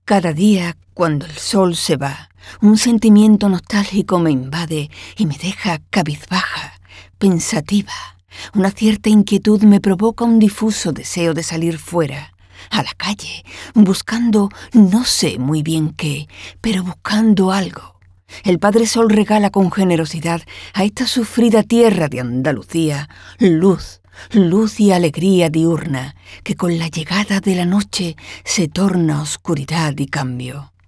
Andaluza femenina adulta media
Andaluz-Voz-femenina-media-0010.mp3